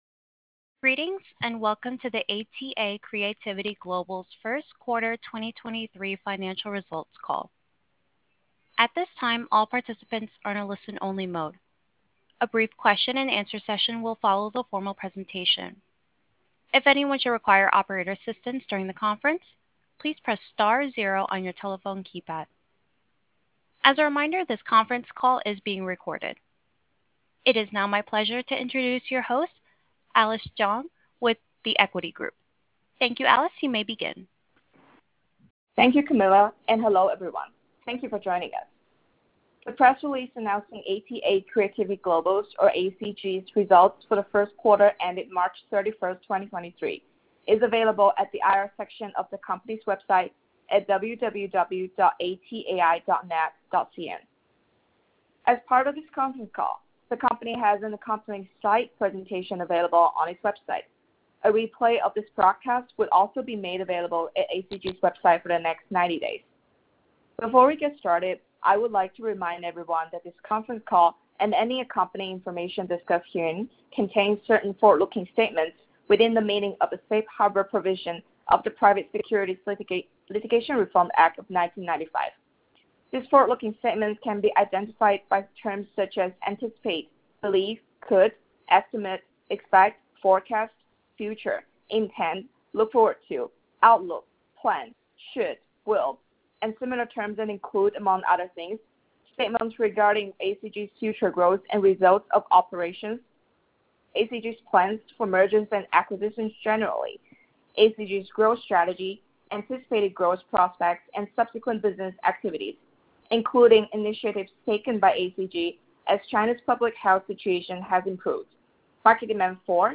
Q1 Fiscal Year 2023 Earnings Conference Call